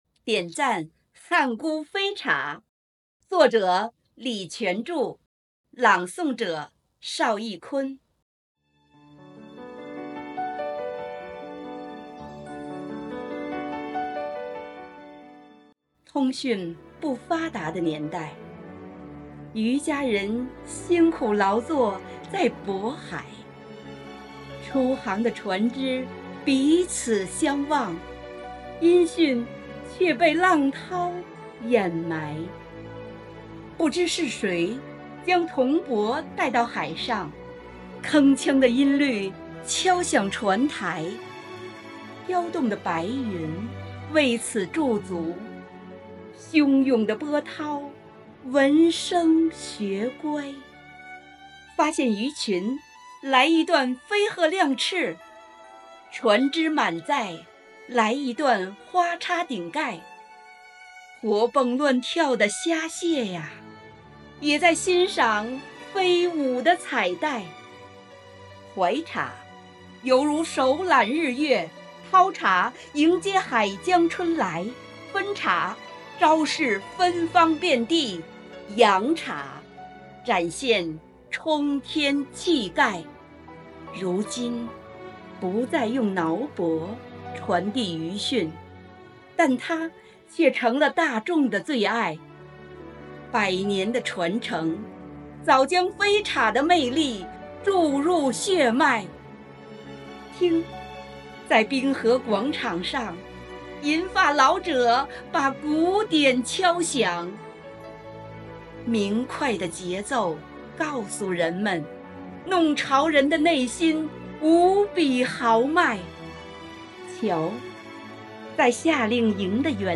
第十届全民创意写作“海河诗人”诗歌创作活动——成年人组（二十）